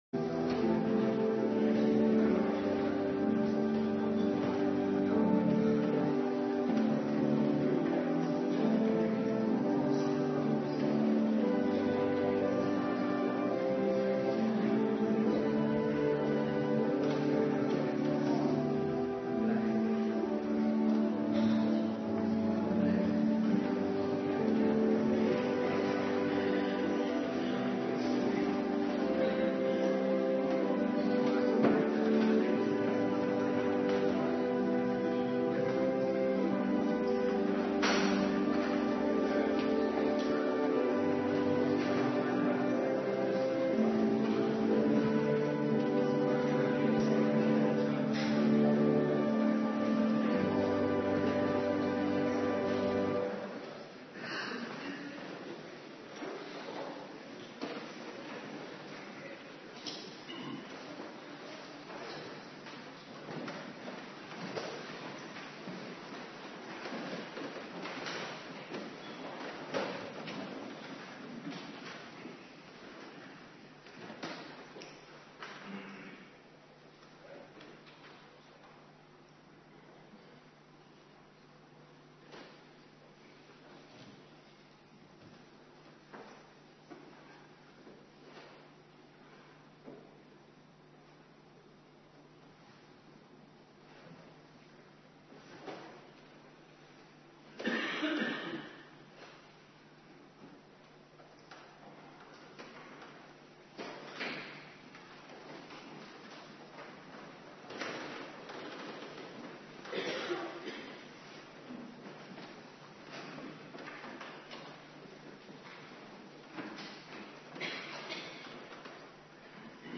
Avonddienst